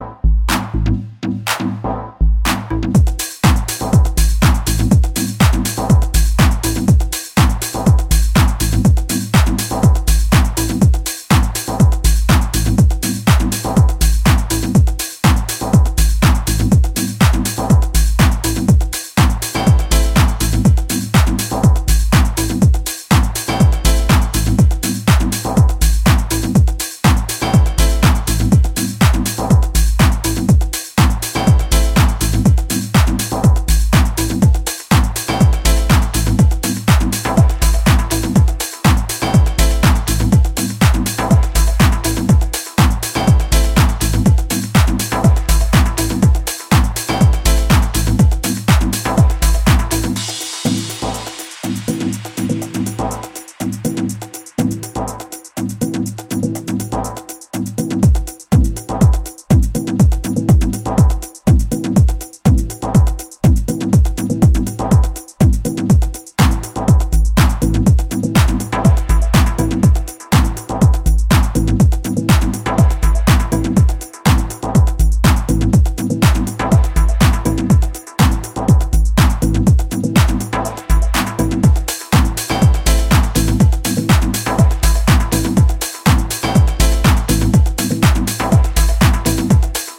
クラシカルでオーセンティックな魅力を放つ、ソリッドなディープ・ハウス群を確かな手腕で展開しています。